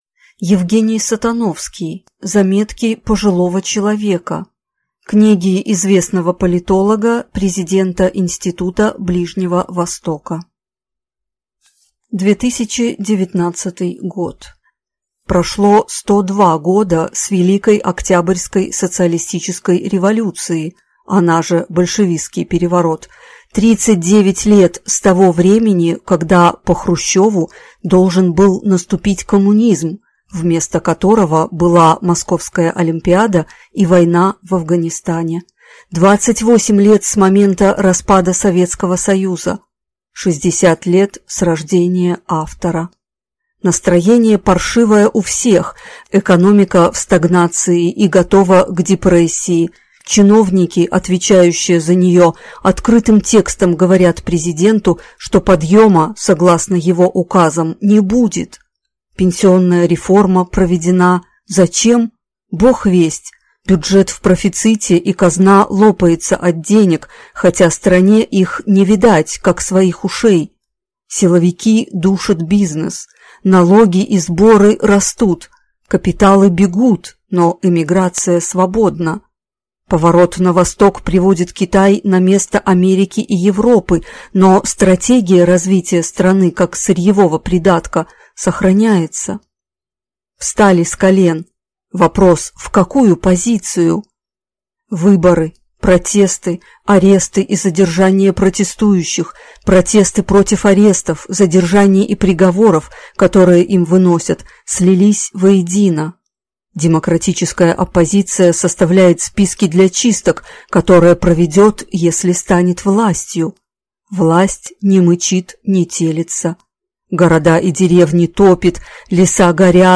Аудиокнига Заметки пожилого человека | Библиотека аудиокниг
Прослушать и бесплатно скачать фрагмент аудиокниги